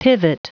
Prononciation du mot pivot en anglais (fichier audio)
Prononciation du mot : pivot